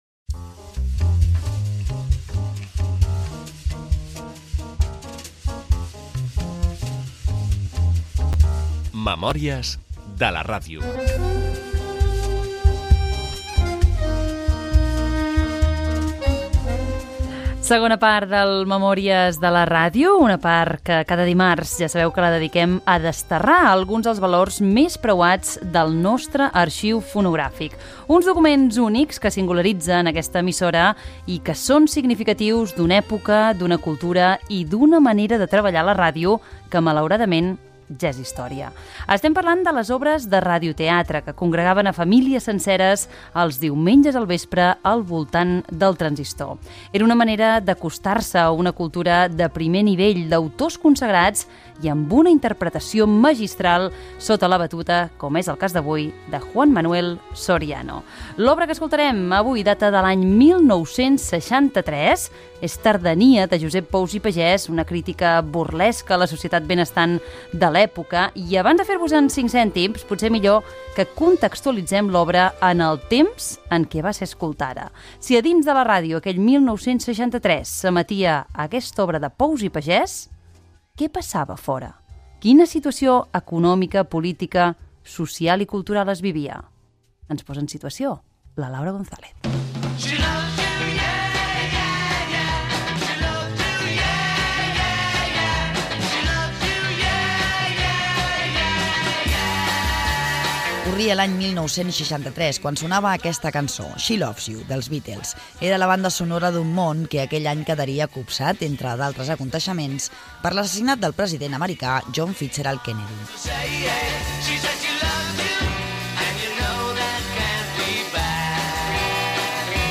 0605a0739e71a28c415b8a02255b6f8708aea825.mp3 Títol Ràdio 4 Emissora Ràdio 4 Cadena RNE Titularitat Pública estatal Nom programa Memòries de la ràdio Descripció Indicatiu del programa, fets significatius de l'any 1963. Versió radiofònica de l'obra "Tardania" de Josep Pous i Pagès feta pel Teatro Invisible de Radio Nacional de España